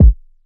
Kick SwaggedOut 9.wav